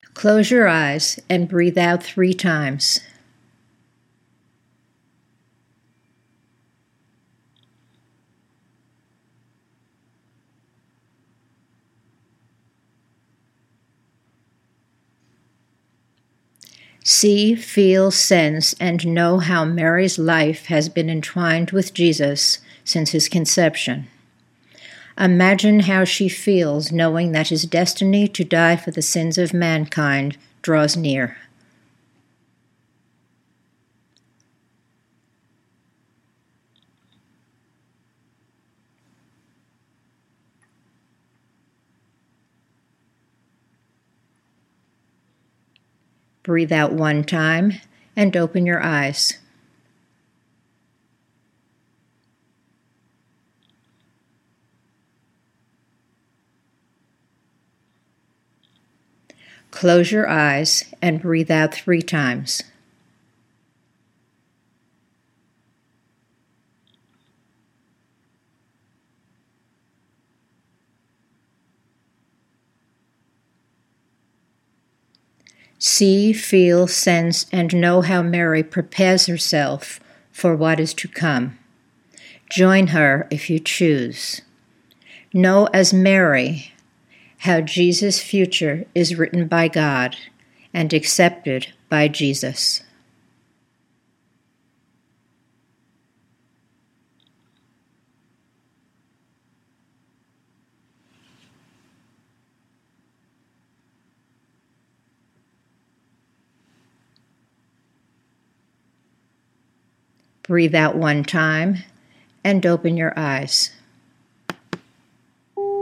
Note:  Where there are two exercises to be done together, there is a space of about ten seconds between them on the tape.